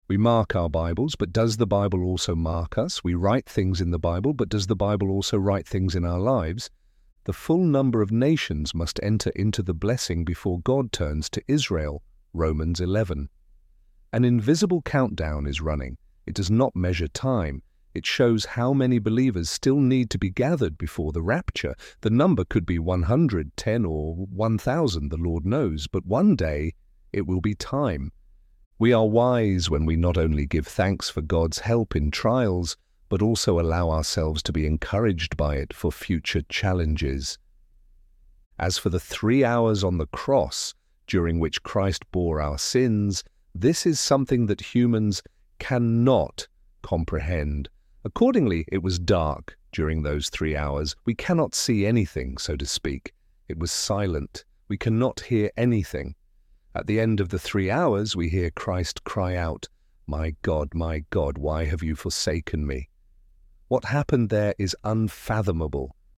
ElevenLabs_Lines__Lessons_4.mp3